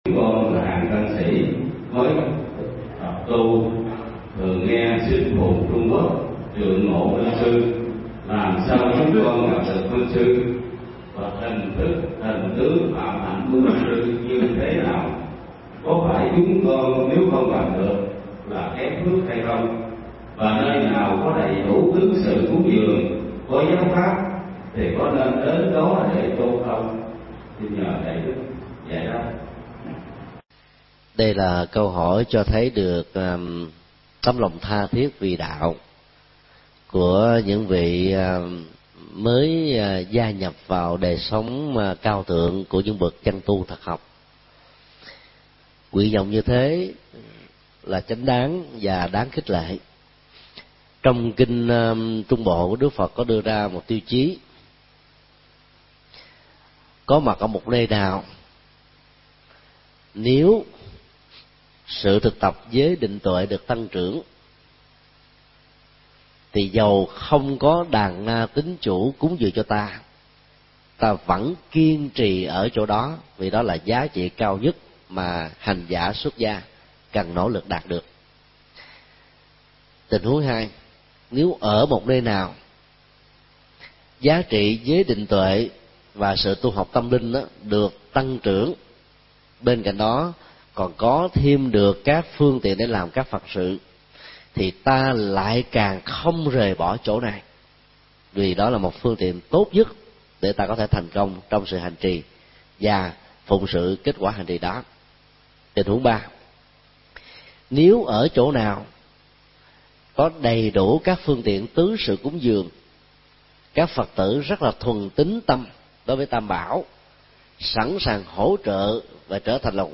Vấn đáp: Tâm nguyện hoằng pháp cho tăng sĩ trẻ